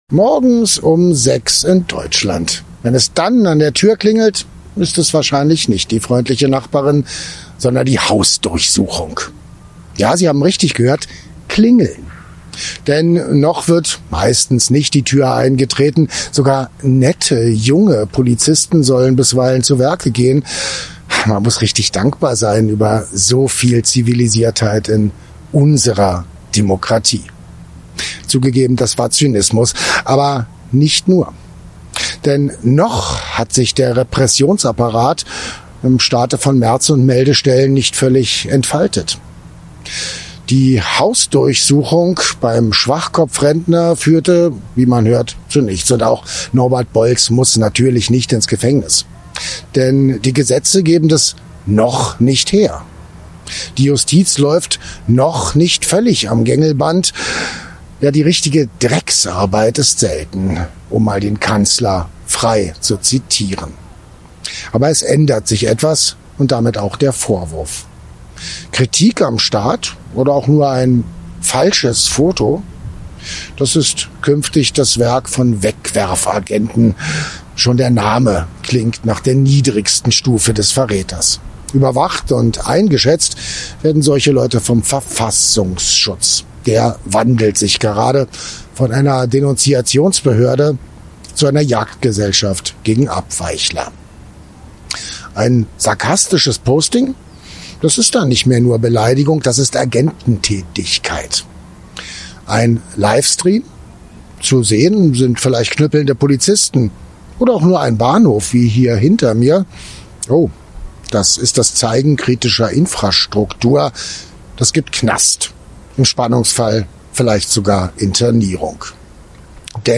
in seinem Kommentar aus Berlin.